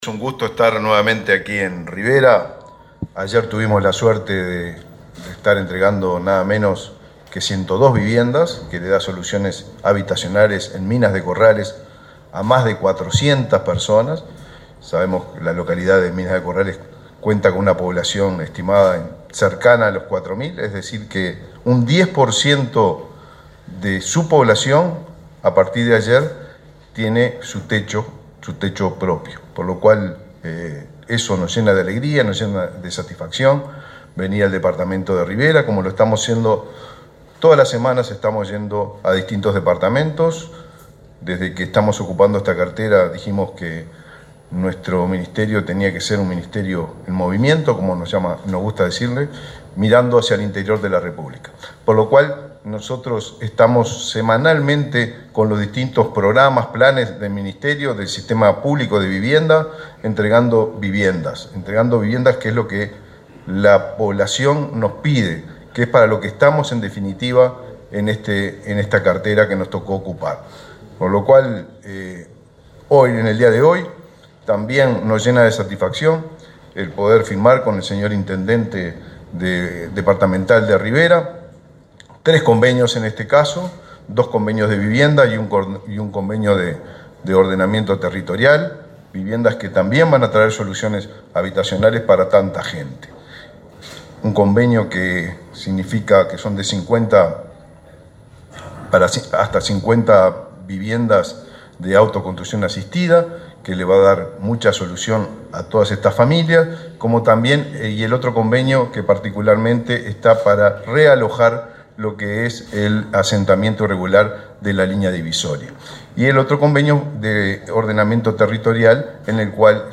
Palabras del ministro de Vivienda, Raúl Lozano
Este viernes 20 en Rivera, el Ministerio de Vivienda y Ordenamiento Territorial (MVOT) y la intendencia firmaron dos convenios para autoconstrucción